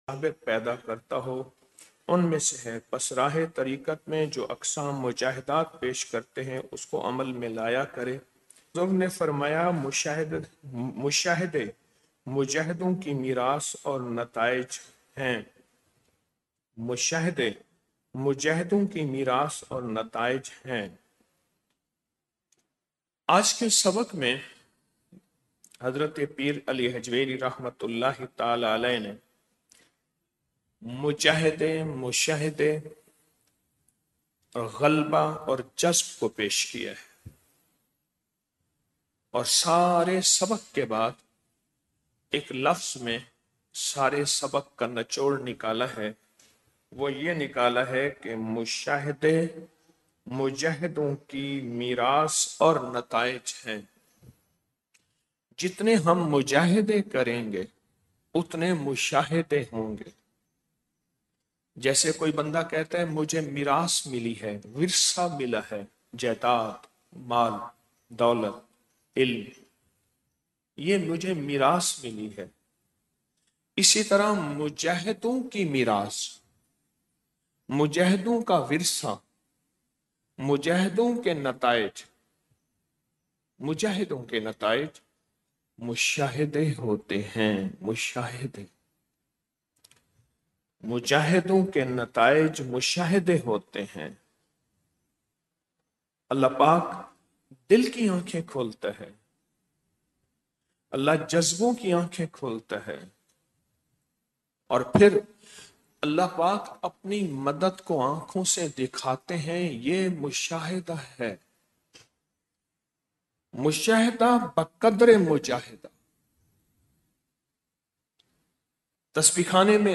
آڈیو درس